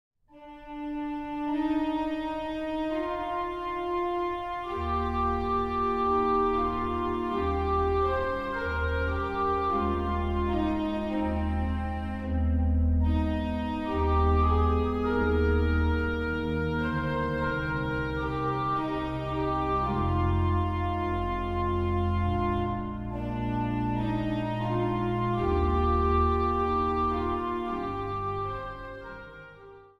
klassieke en populaire werken op het orgel
Instrumentaal | Orgel